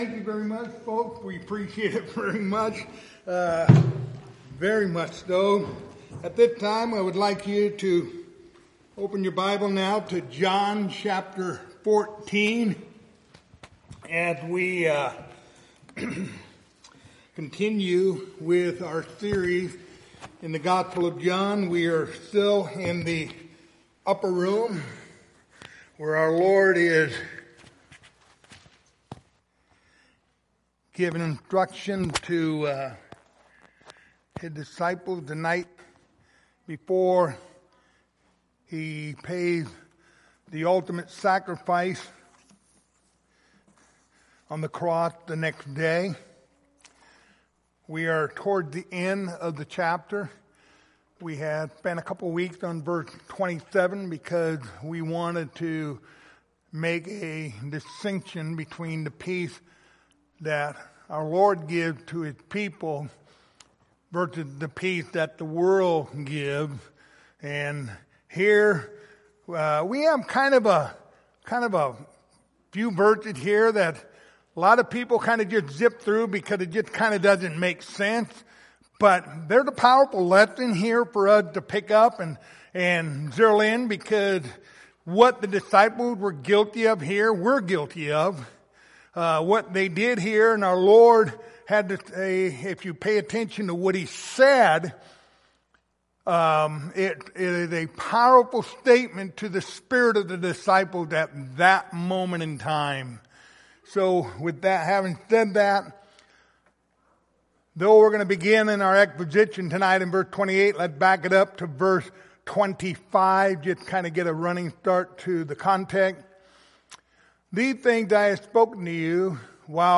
Passage: John 14:28-31 Service Type: Wednesday Evening